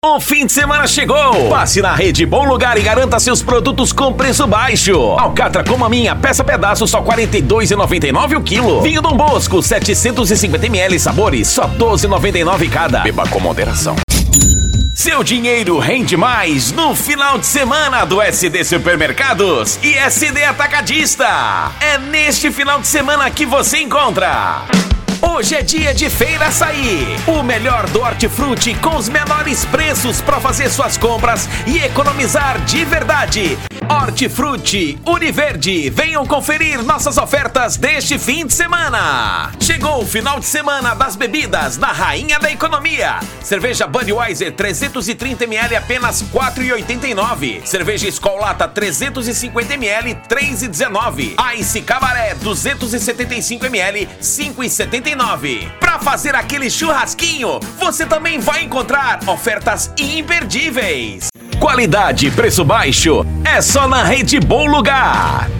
DEMONSTRATIVO SUPERMERCADO, ATACADO,MERCADO E HORTIFRUT:
Impacto
Animada